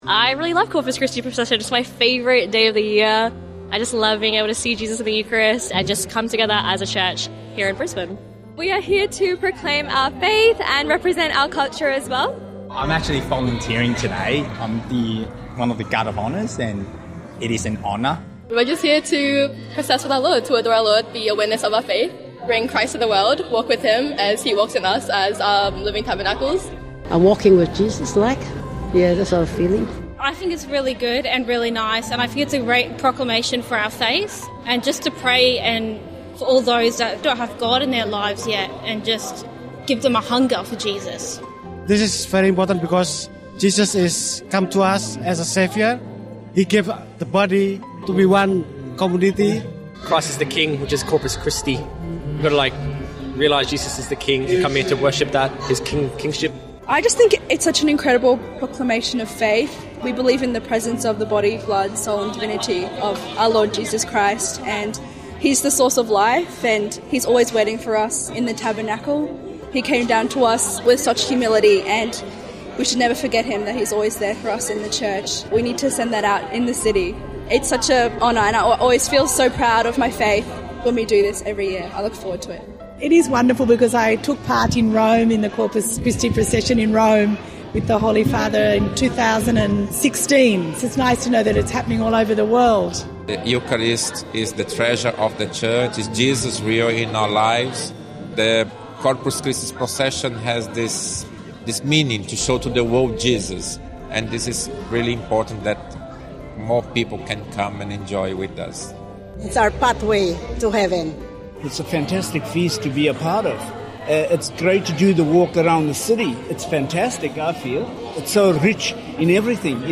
Corpus Christi Procession Highlights
Over 5,000 Catholics processed through the streets of Brisbane in the Corpus Christi celebration, honouring Jesus truly present in the Eucharist. This year’s procession was especially meaningful, as Archbishop Mark Coleridge led the celebration for the final time.